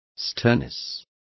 Complete with pronunciation of the translation of sternness.